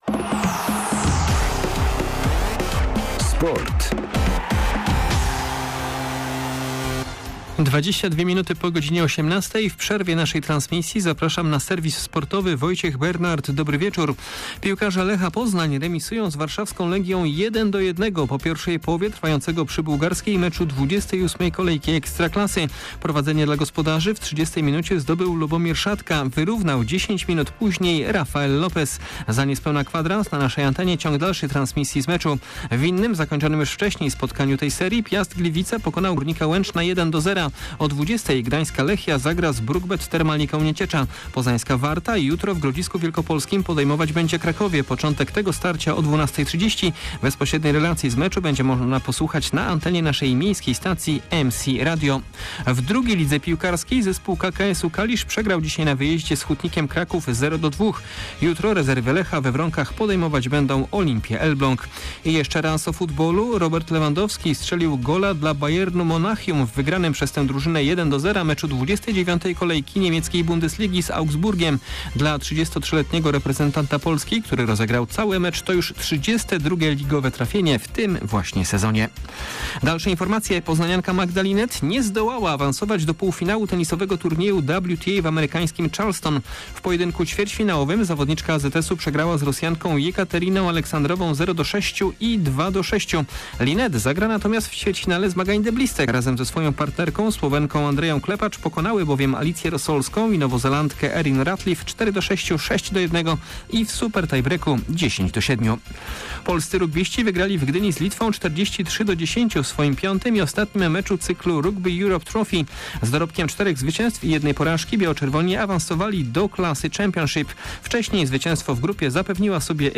09.04.2022 SERWIS SPORTOWY GODZ. 18:20
Serwis tym razem w przerwie transmisji meczu Lecha z Legią. A w nim między innymi o porażce poznanianki Magdy Linette w tenisowym turnieju w Charleston, kwalifikacjach do Grand Prix Australii Formuły 1 oraz inauguracji rundy wiosennej w Superlidze hokeja na trawie.